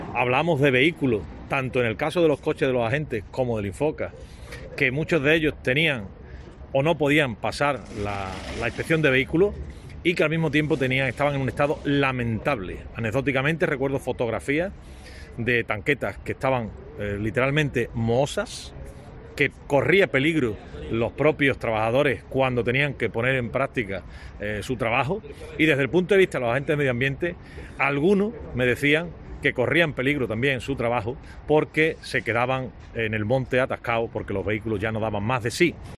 Álvaro Burgos, delegado de Agricultura y Pesca en Huelva